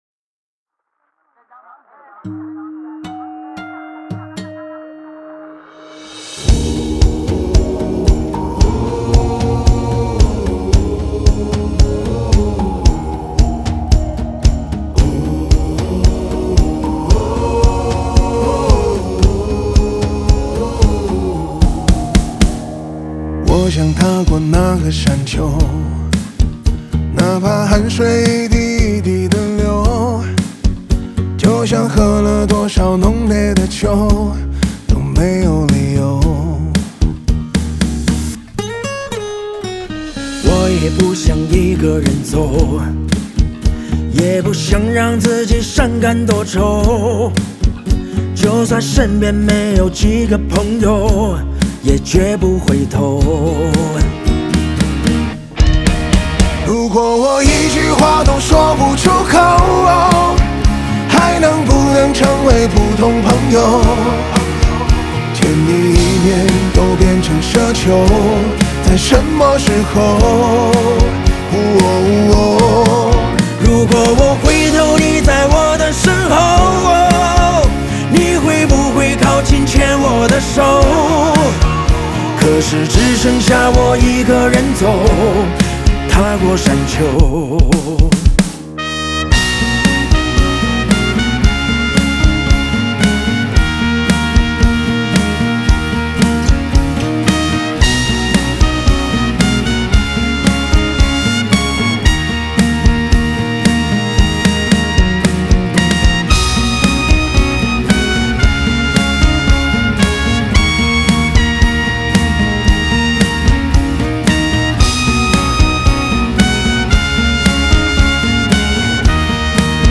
Ps：在线试听为压缩音质节选，体验无损音质请下载完整版 Wu……